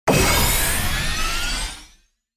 Level Up Sfx Sound Button - Free Download & Play